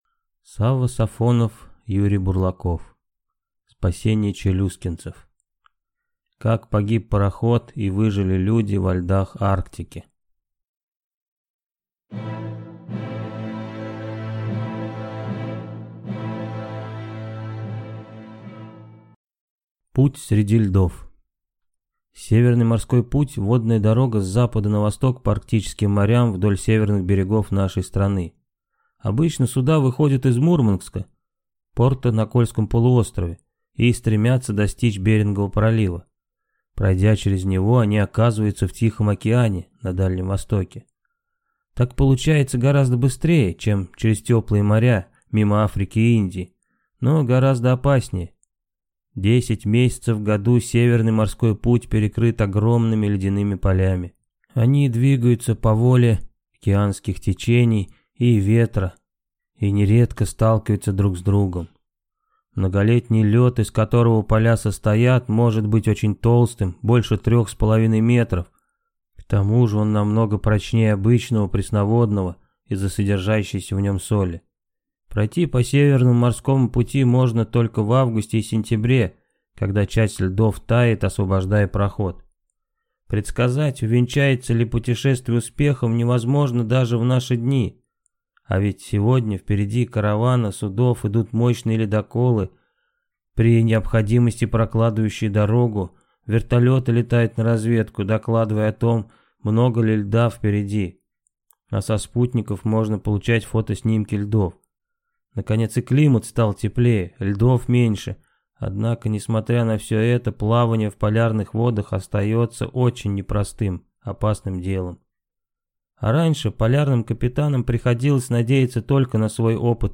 Аудиокнига Спасение челюскинцев | Библиотека аудиокниг